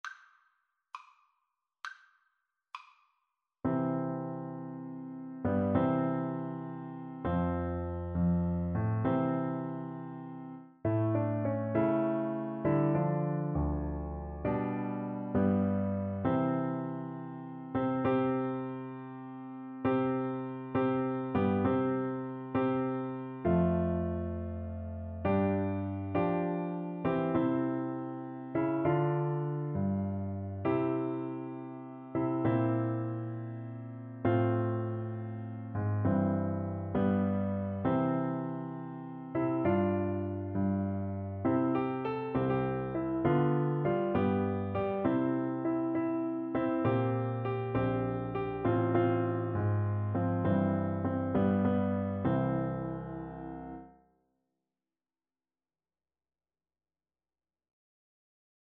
6/8 (View more 6/8 Music)
Piano Duet  (View more Intermediate Piano Duet Music)
Classical (View more Classical Piano Duet Music)